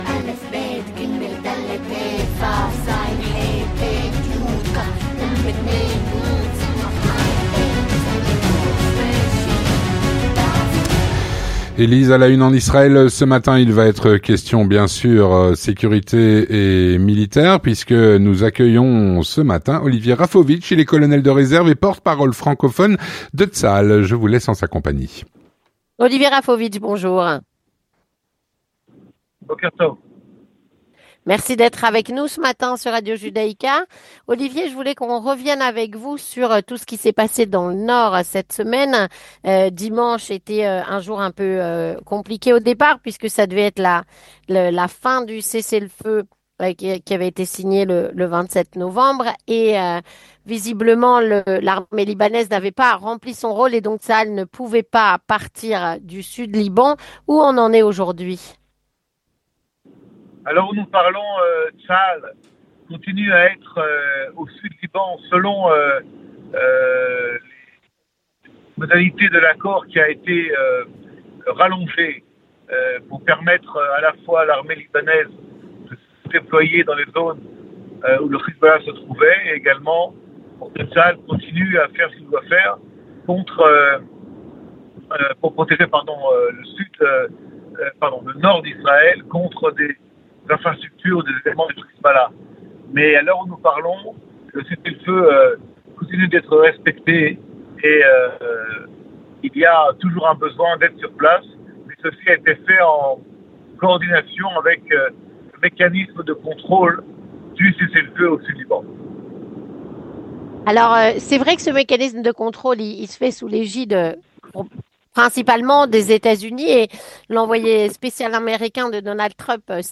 Il est au micro